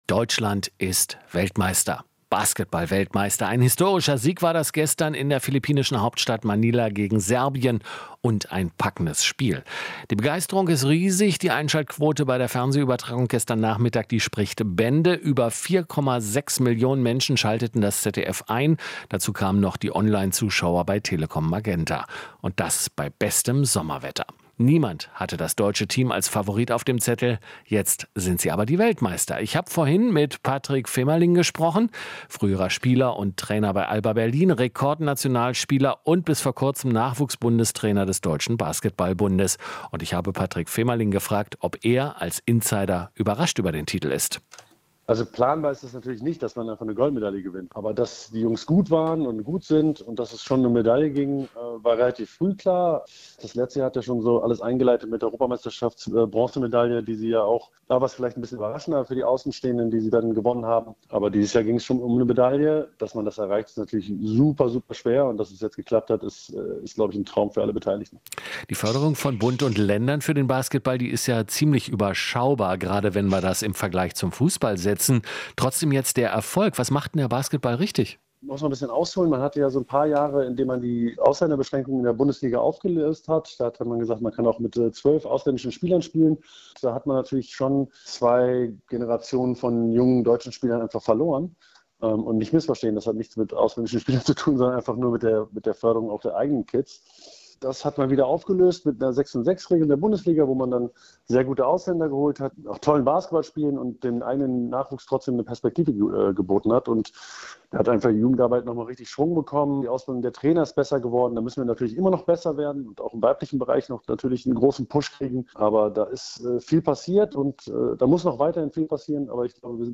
Interview - WM-Sieg: Alba-Ikone Femerling hofft auf einen Basketball-Hype